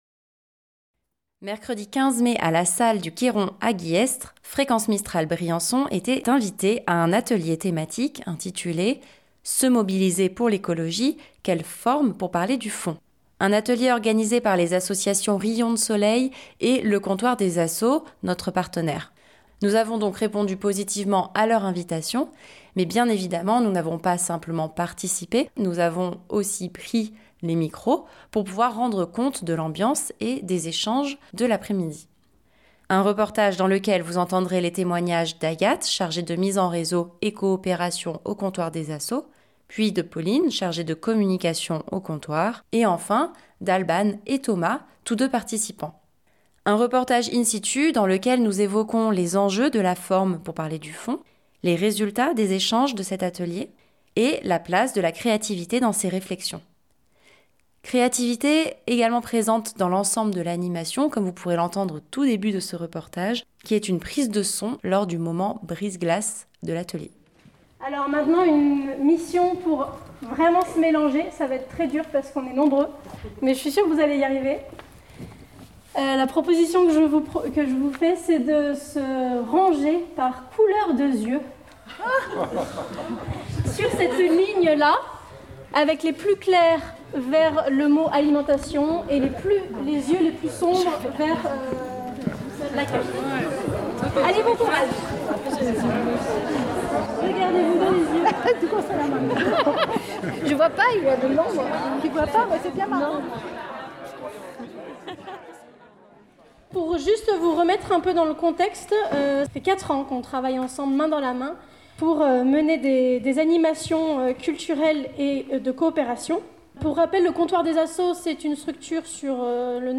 Bien évidemment, nous avons pris les micros pour pouvoir rendre compte de l'ambiance et des discussions.
Un reportage in situ dans lequel nous évoquons les enjeux de la forme (pour parler du fond), les résultats des échanges de cet atelier et la place de la créativité dans ces réflexions.